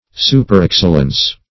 Search Result for " superexcellence" : The Collaborative International Dictionary of English v.0.48: Superexcellence \Su`per*ex"cel*lence\, n. Superior excellence; extraordinary excellence.